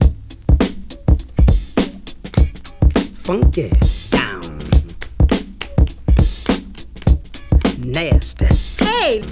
• 103 Bpm High Quality Drum Loop Sample C# Key.wav
Free breakbeat sample - kick tuned to the C# note. Loudest frequency: 643Hz
103-bpm-high-quality-drum-loop-sample-c-sharp-key-dac.wav